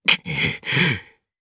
DarkKasel-Vox_Sad_jp.wav